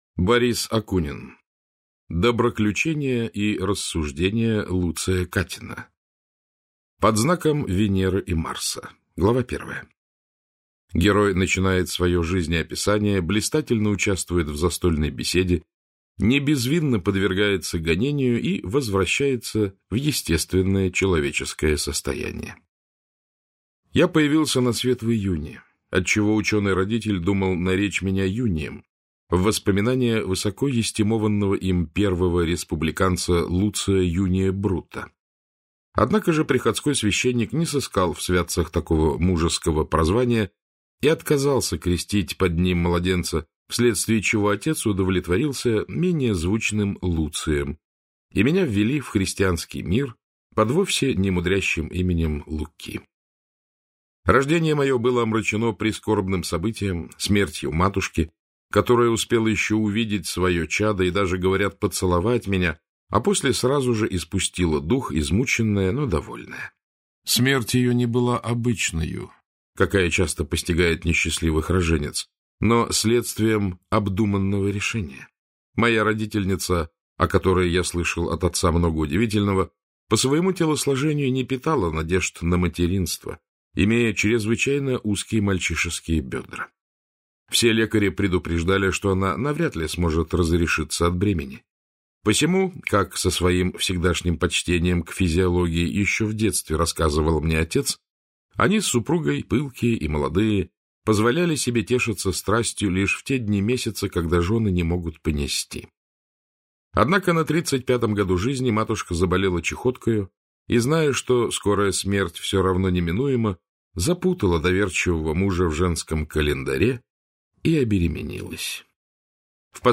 Аудиокнига Доброключения и рассуждения Луция Катина - купить, скачать и слушать онлайн | КнигоПоиск